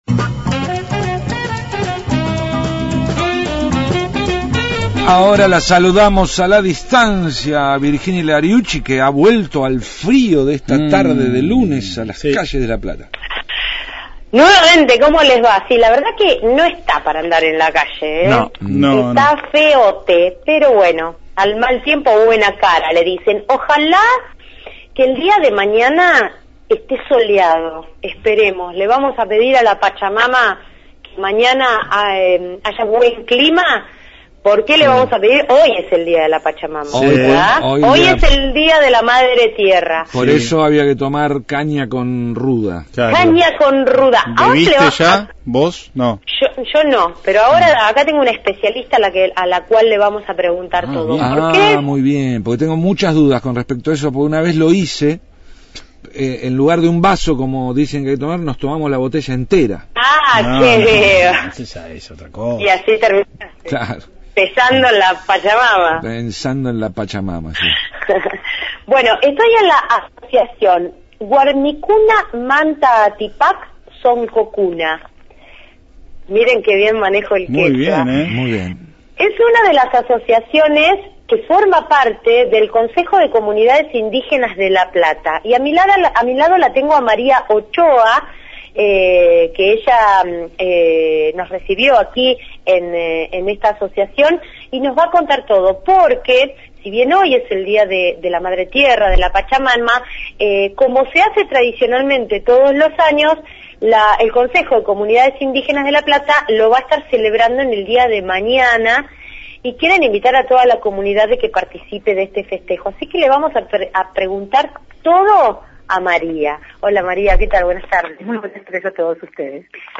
desde la calle 37 entre 118 y 119 por el día de la madre tierra